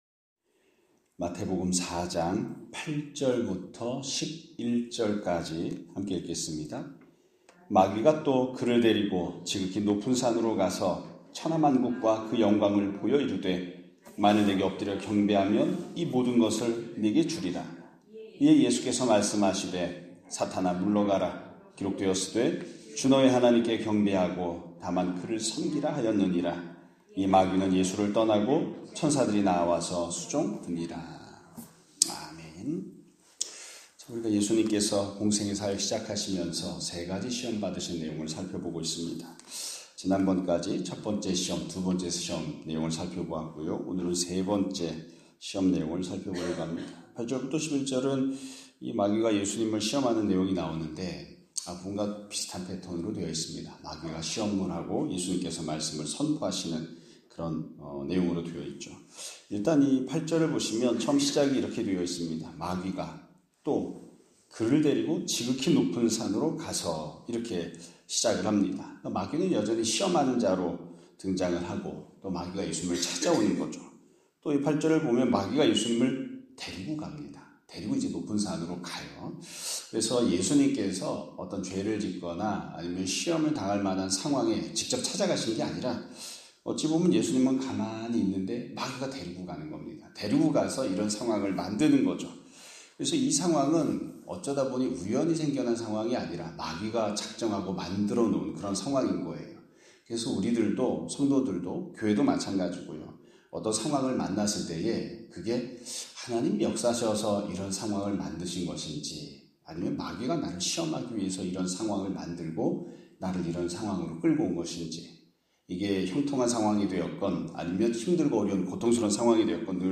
2025년 4월 28일(월요일) <아침예배> 설교입니다.